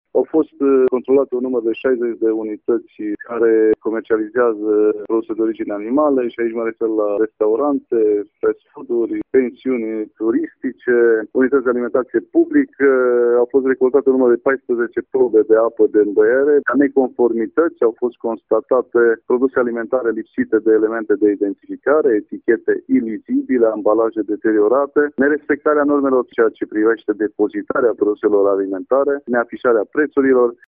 Directorul Direcției Sanitar Veterinare și pentru Siguranța Alimentelor Mureș, Vasile Oprea: